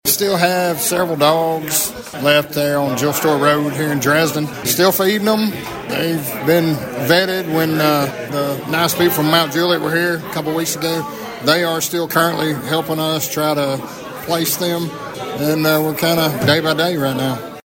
Weakley County Sheriff Terry McDade gave Thunderbolt Radio News an update on the surplus of dogs found at a house in Dresden about a month ago…